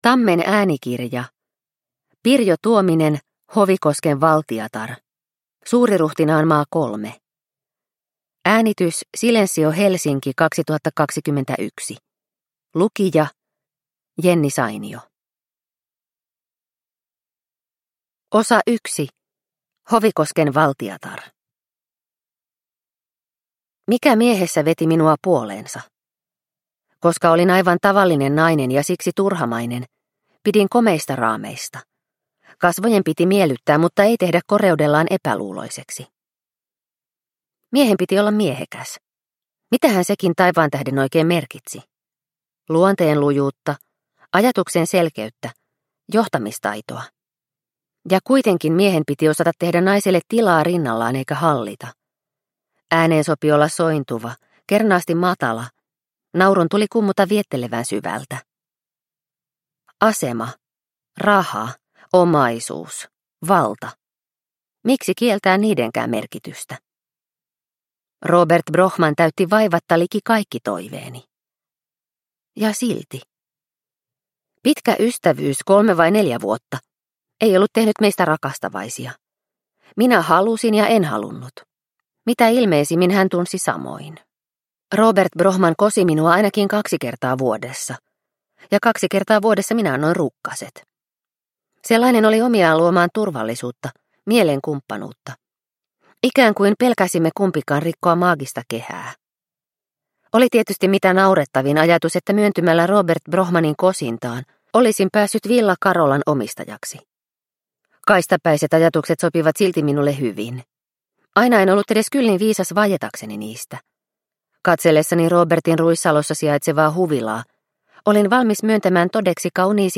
Hovikosken valtiatar – Ljudbok – Laddas ner